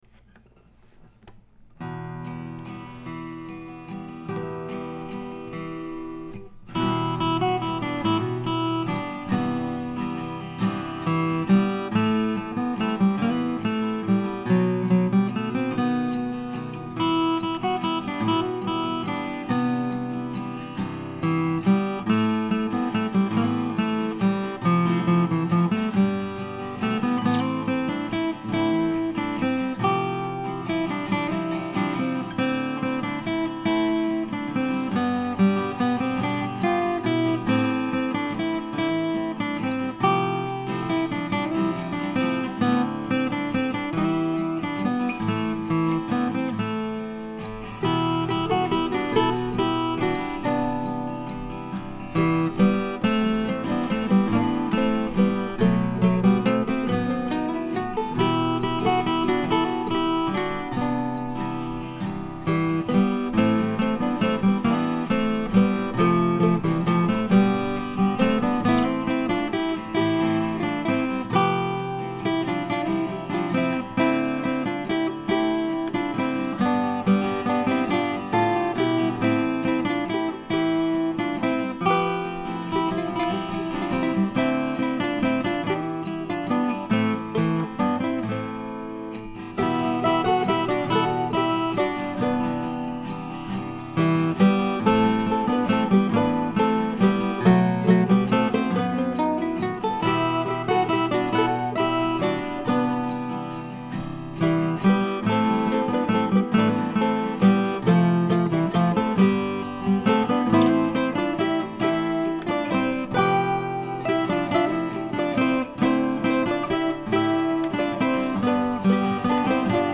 She was looking for a waltz to help celebrate two special anniversaries in her life, one a 40th and one a 50th.
In fact I chose to play the harmony part down an octave last night because I decided to play the melody on guitar the first time through, instead of on mandolin.